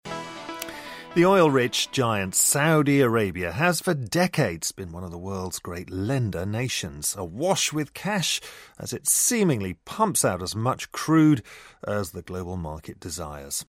【英音模仿秀】沙特将公布五年计划 听力文件下载—在线英语听力室